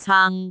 speech
syllable
pronunciation
caang3.wav